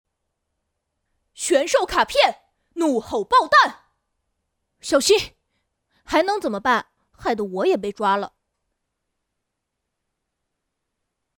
国语儿童素人 、女动漫动画游戏影视 、看稿报价女游11 国语 女声 游戏 正太-玄兽战记-康宁 素人